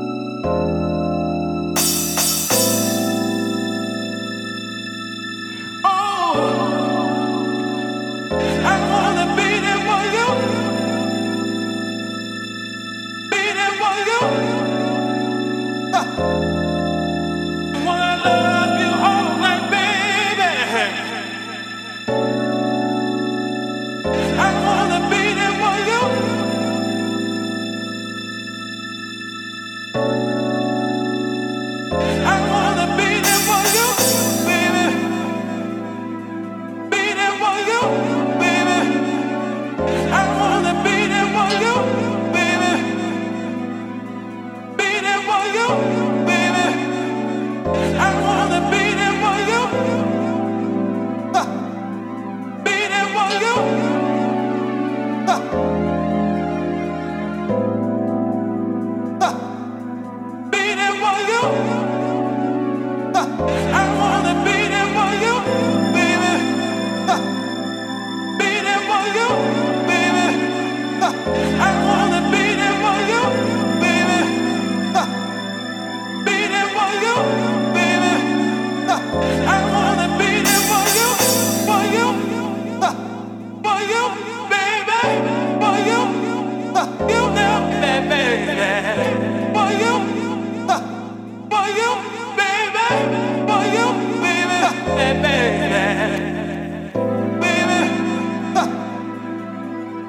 絢爛なピアノとソウルフルなヴォイス・サンプルがウォームでオーセンティックな魅力を放つディープ・ハウス